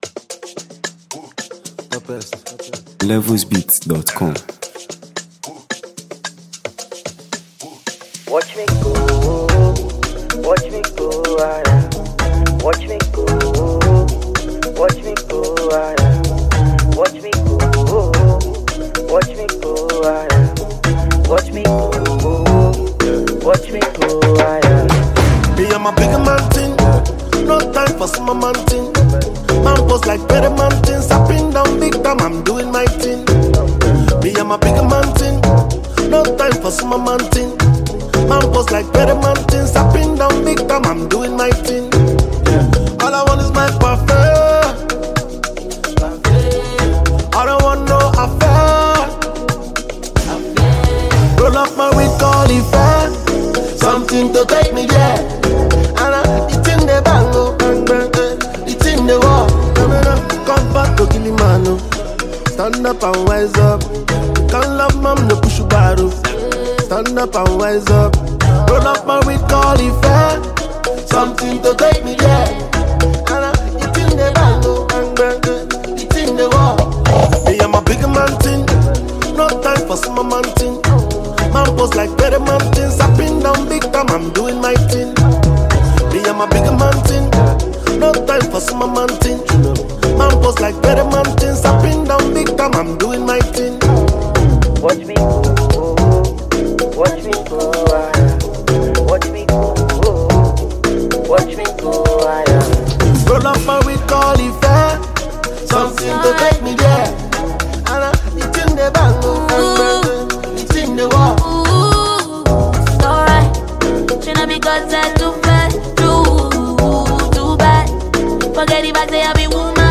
captivating Afro-fusion sounds